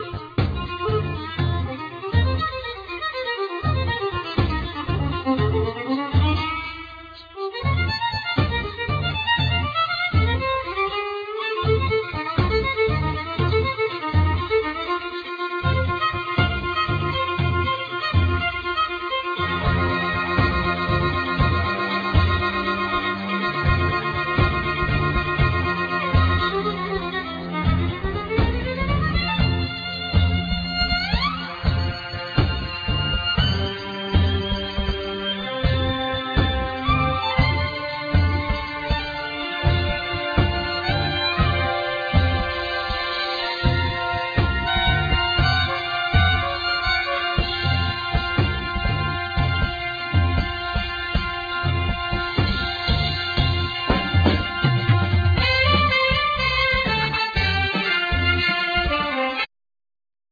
Alto & Tenor Saxophone
Viloin
Keyboards
Bass
Drums